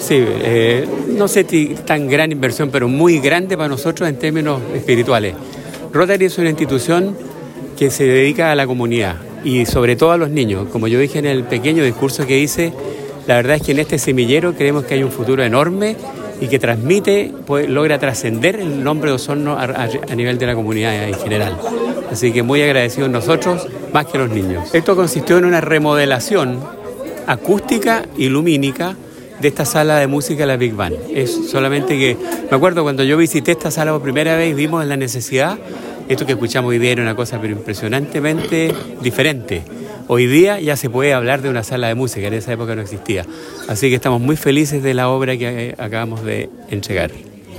señaló durante la ceremonia de inauguración que esta contribución fue concebida como un aporte relevante al desarrollo cultural de Osorno, subrayando el valor de apoyar iniciativas que fomenten la creatividad y el talento en las nuevas generaciones.